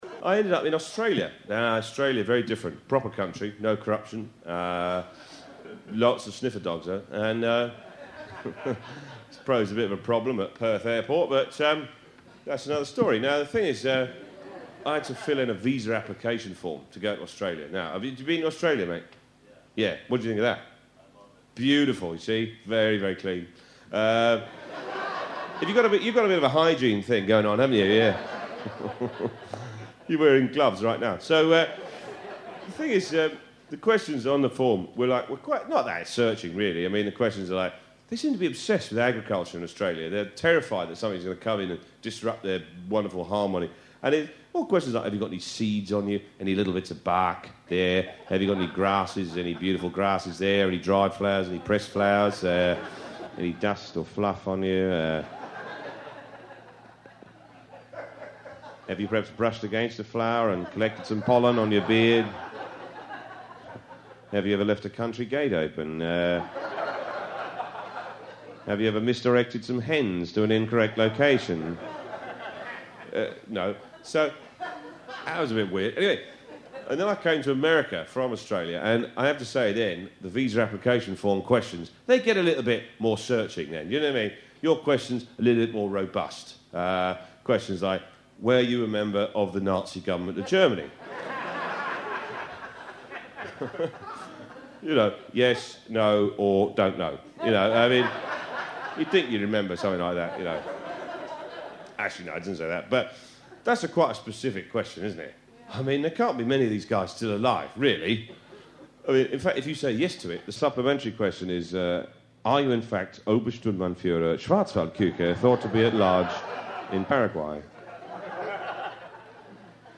This show was recorded in New York last year, though any other details are sketchy.